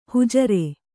♪ hujare